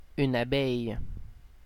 ÄäntäminenFrance (Normandie):
• IPA: [a.bɛj]